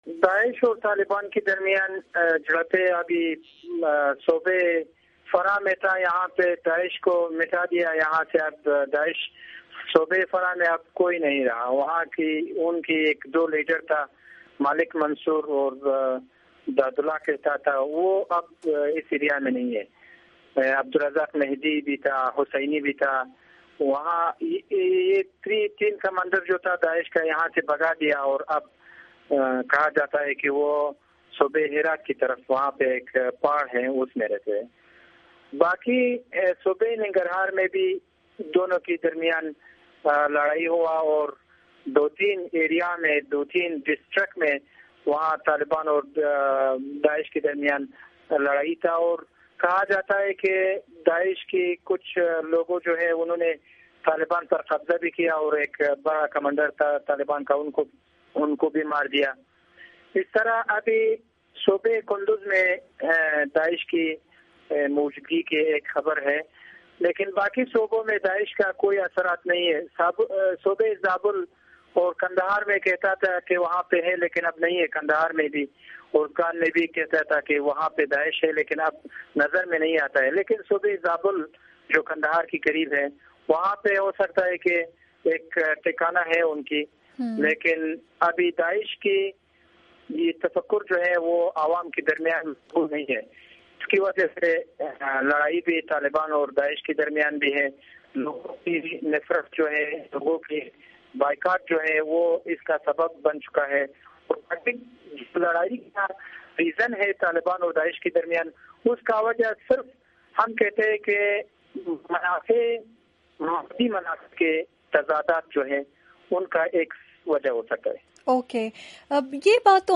پروگرام جہاں رنگ میں افغان صوبے فرح کے گورنر کا انکشاف